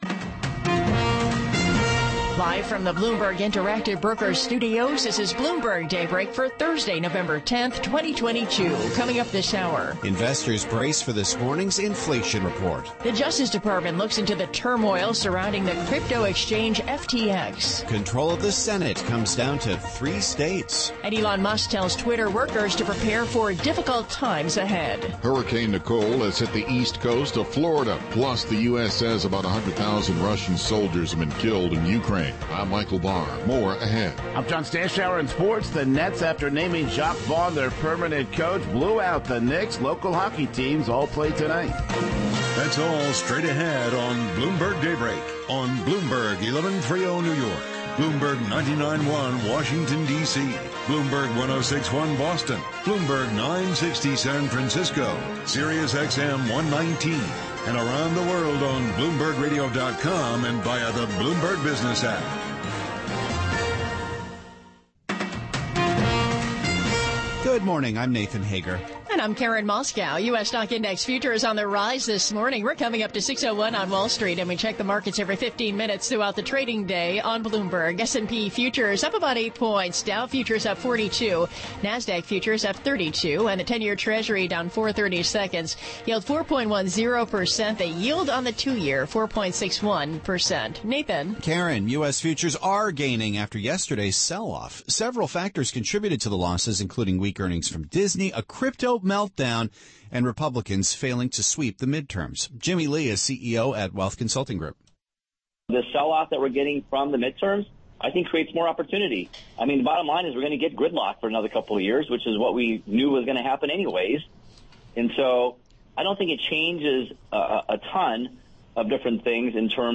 Bloomberg Daybreak: November 10, 2022 - Hour 2 (Radio)